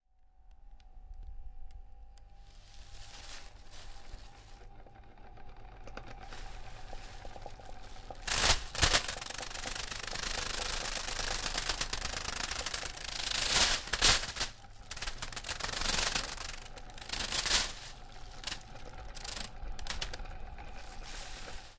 旧风扇 "玻璃纸袋在风扇1上（平静）。
描述：把一个玻璃袋放在风扇叶片上，然后听。